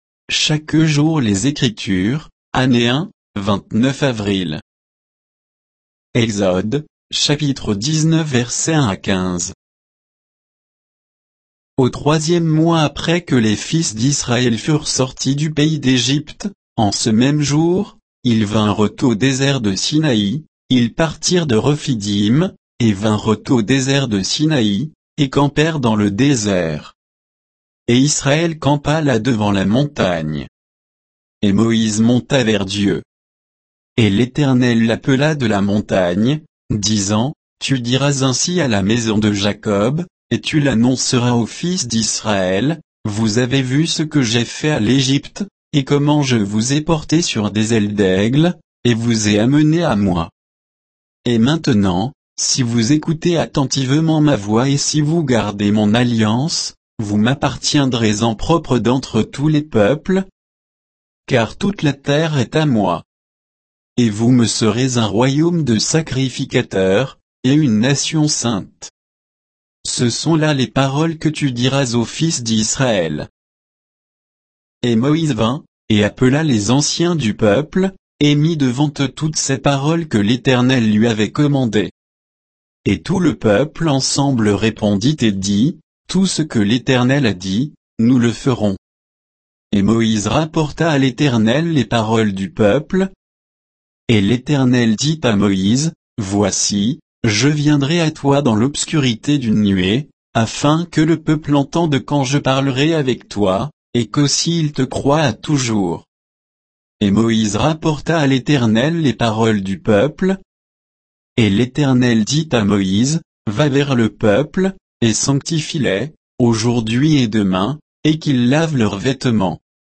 Méditation quoditienne de Chaque jour les Écritures sur Exode 19, 1 à 15